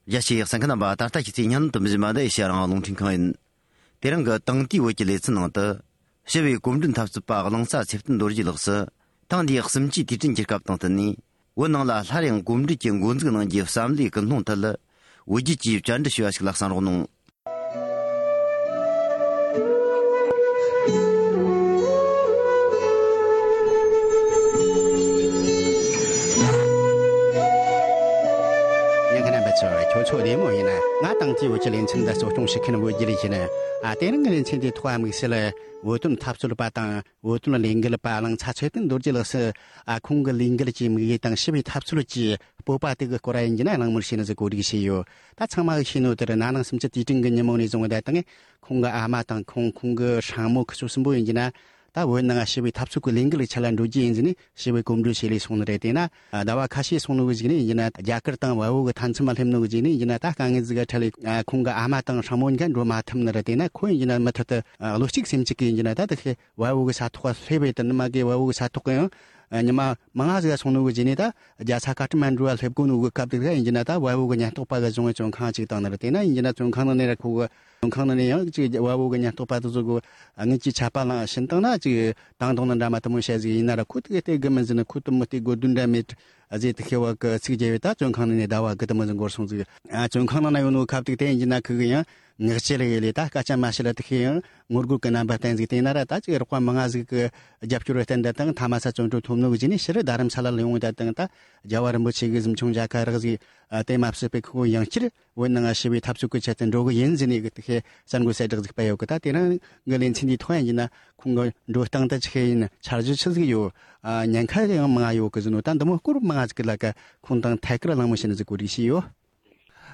གླེང་མོལ་ཞུས་པའི་ལས་རིམ་ཞིག་གསན་རོགས་གནང་།།